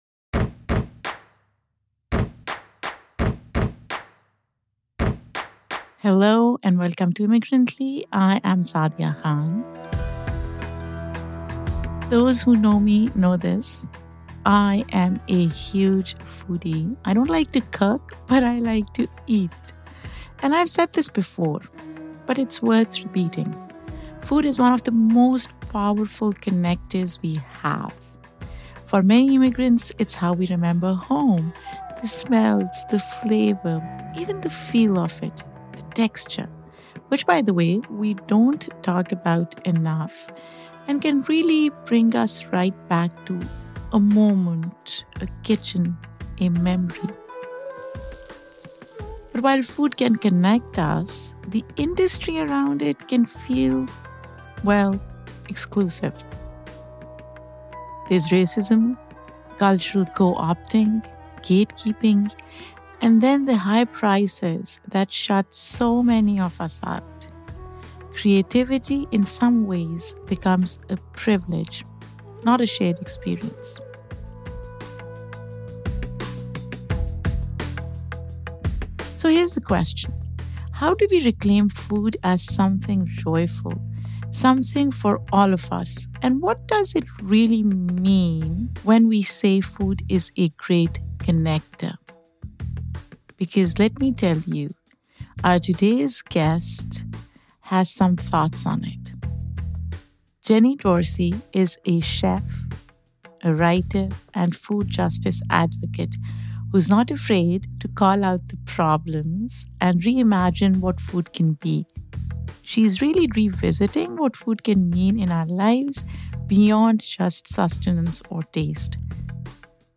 From childhood shame to the myth of fine dining, this is a raw conversation about who controls the narrative and who’s left out.